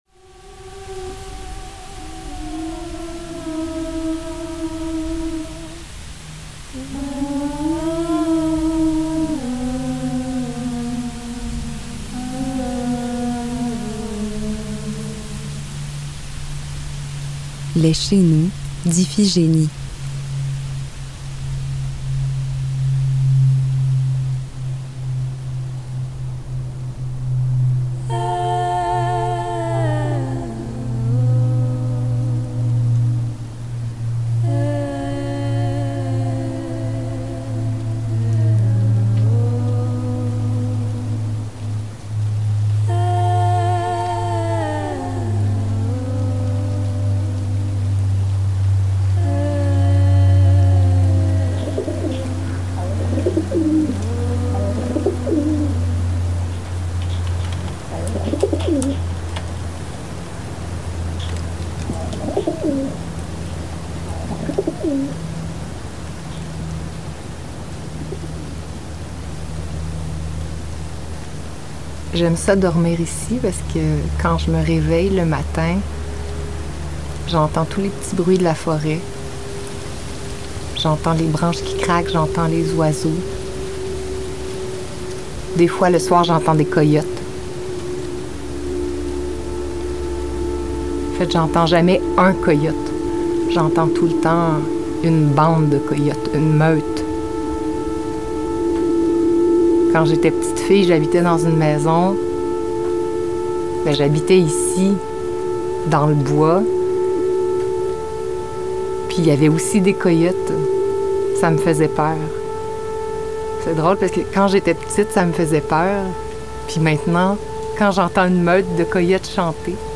2023, 11:22 min., microhistoire de DEVENIR CHEZ-NOUS, s.-t. ang, couleur, 16:9, HDSR 23.976, stéréo
En conversation avec
La voix du pigeon
Sound recording of Rock Dove Columba livia at London, Great Britain.